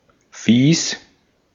Ääntäminen
IPA : /dɪsˈɡʌstɪŋ/